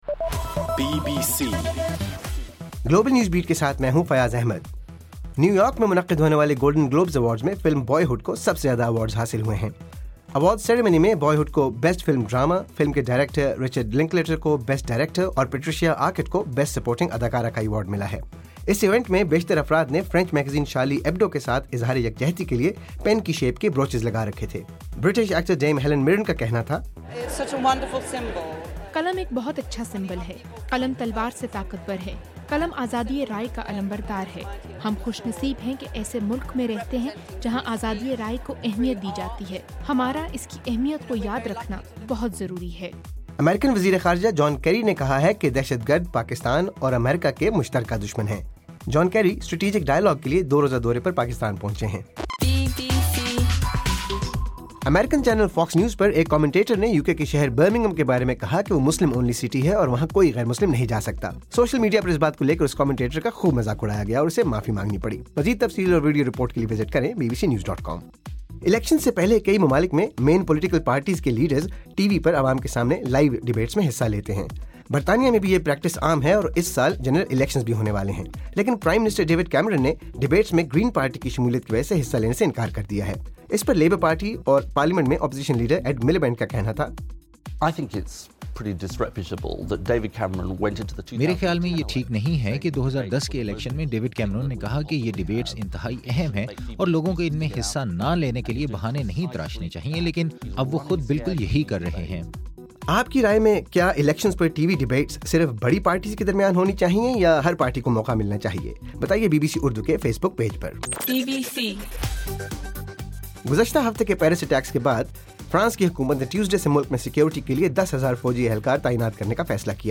جنوری 12: رات 12 بجے کا گلوبل نیوز بیٹ بُلیٹن